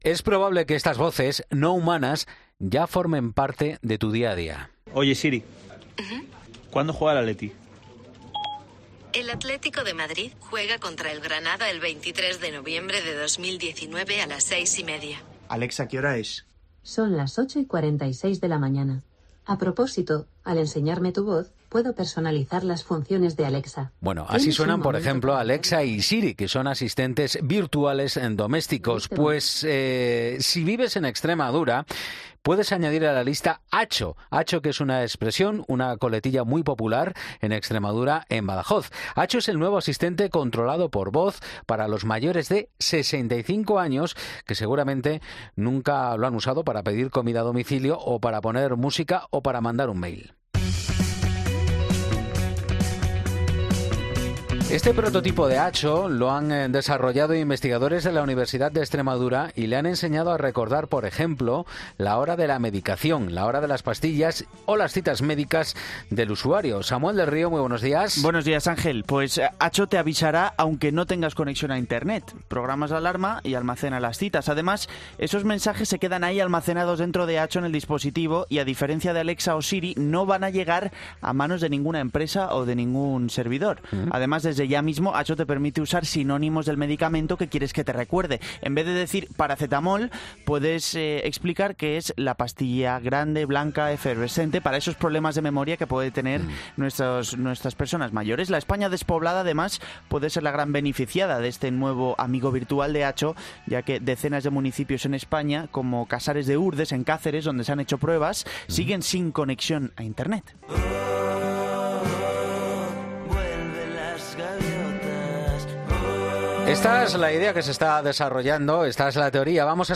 Así suenan los asistentes virtual domésticos Alexa, Siri, Cortana y OK Google.